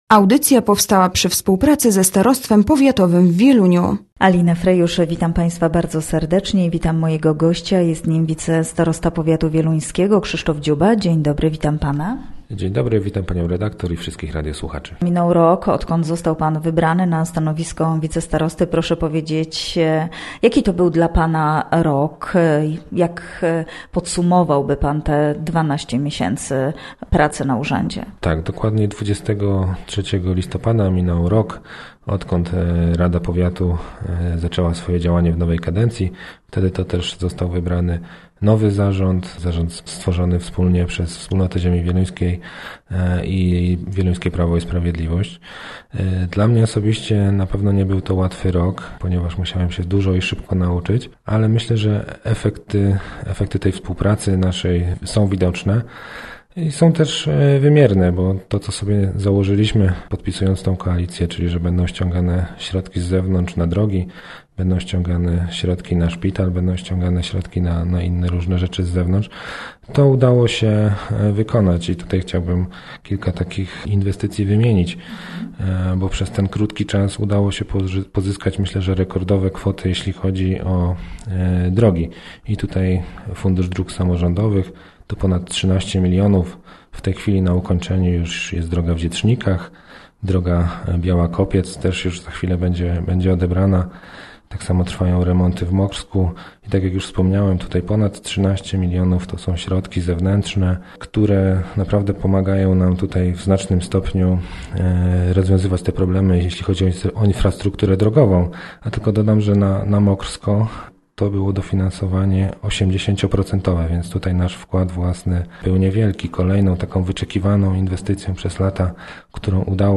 Gościem Radia ZW był wicestarosta wieluński, Krzysztof Dziuba